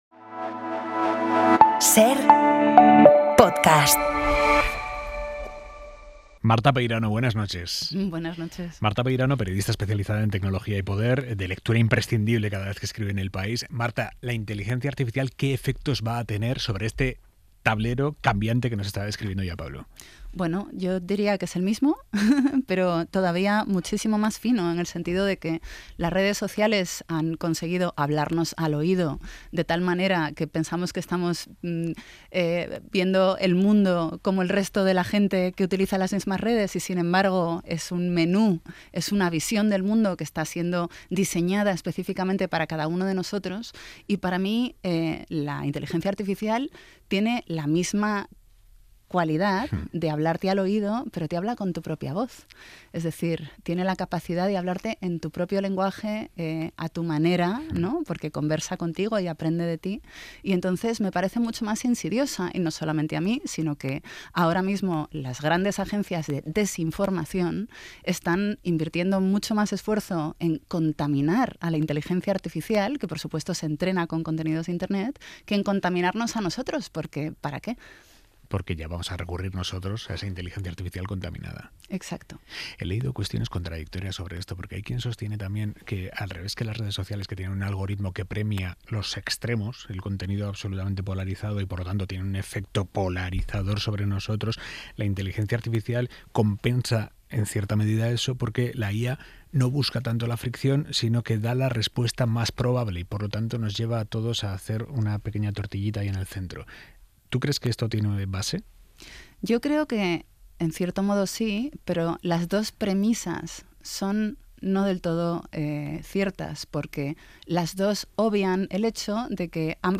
Aimar Bretos entrevista a Marta Peirano, periodista especializada en tecnología y poder. Hemos pasado de un optimismo desaforado con todo lo que tiene que ver con redes sociales e inteligencia artificial a ser más cautos, prudentes.